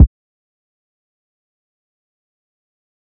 Cass_Kick3.wav